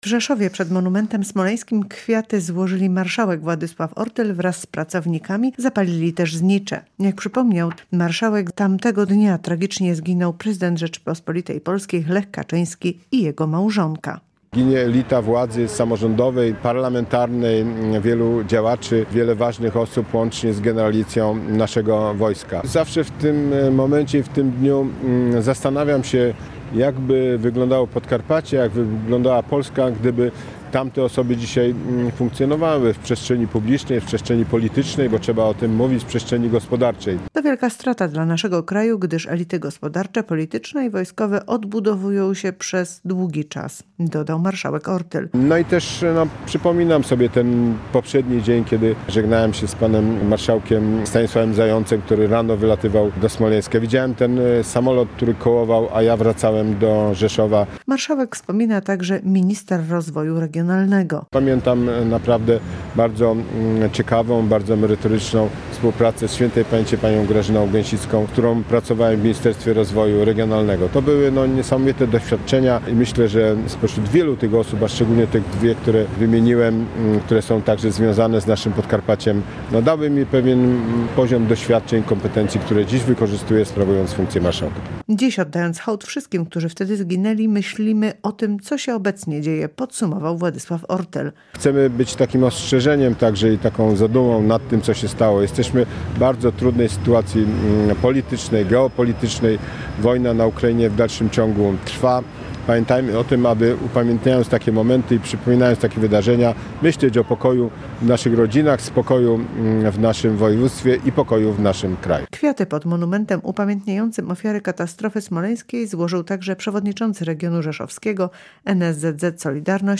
Relacja: